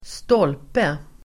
Uttal: [²st'ål:pe]